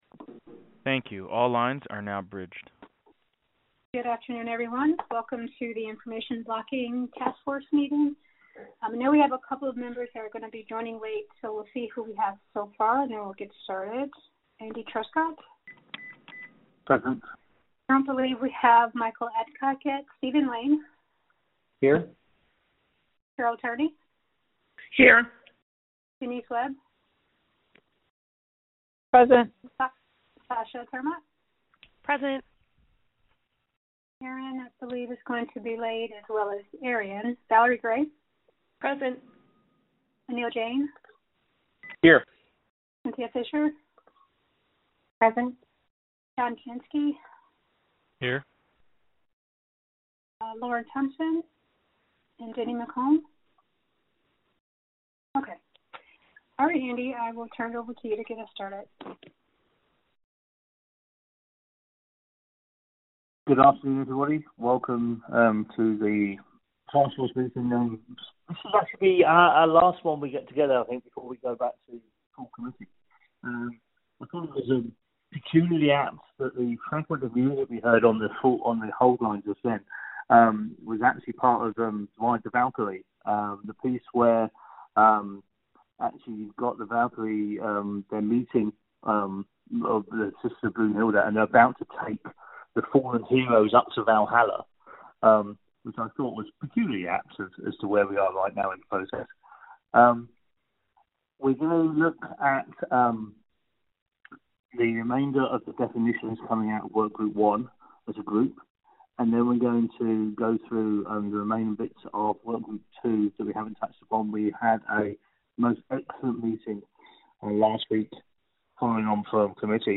2019-04-17_IACC_VirtualMeeting_Audio